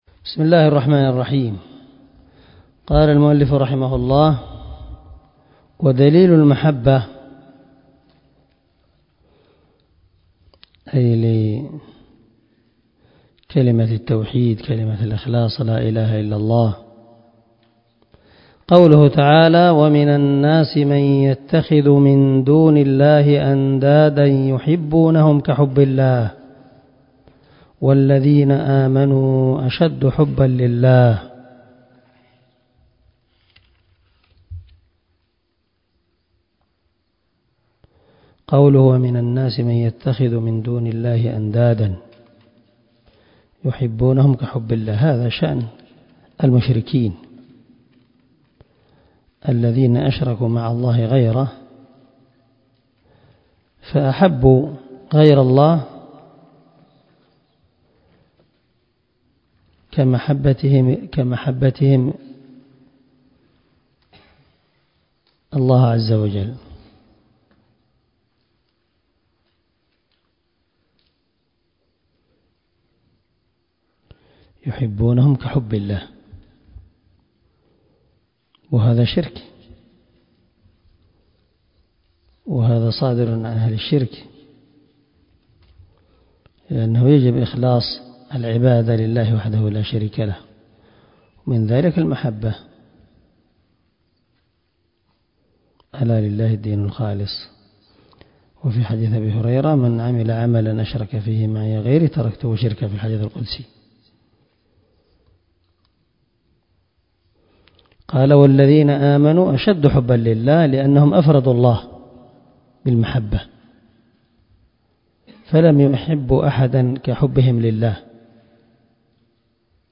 🔊الدرس 8 أدلة شرط المحبة ( من شرح الواجبات المتحتمات)
الدرس-8-أدلة-شرط-المحبة.mp3